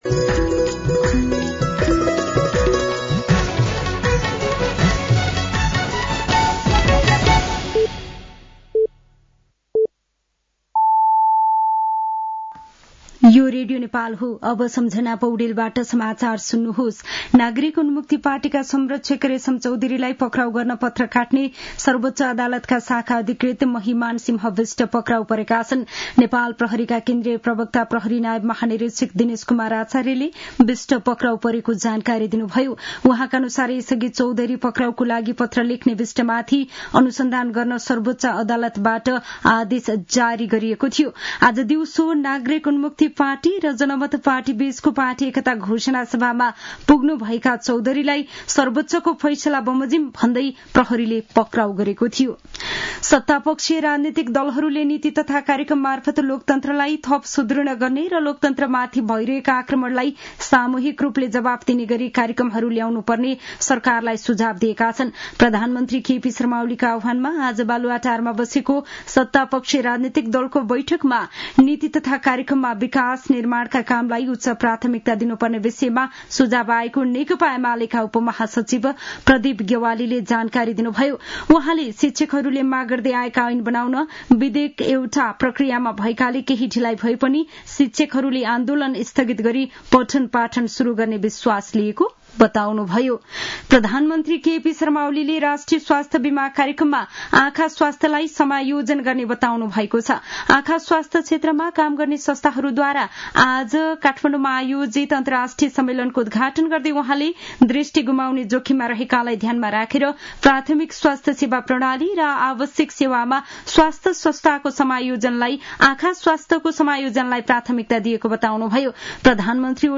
साँझ ५ बजेको नेपाली समाचार : १७ वैशाख , २०८२